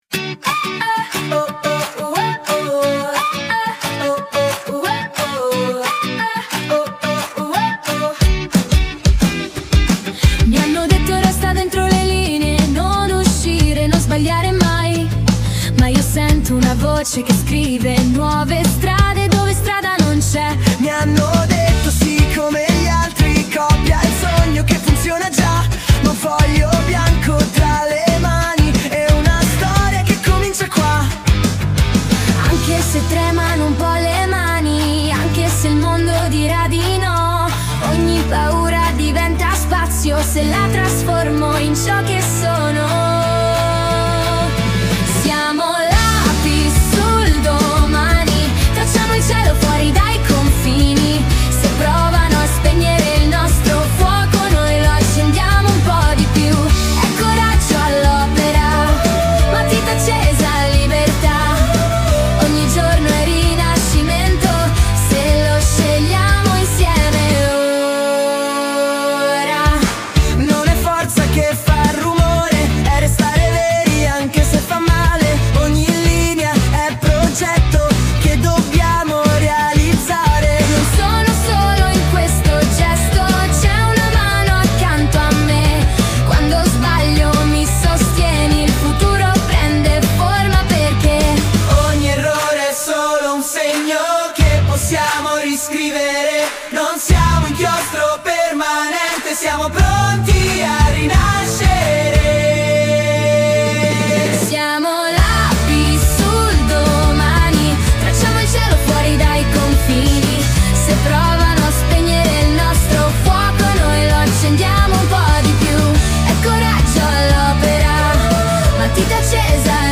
File audio INNO della canzone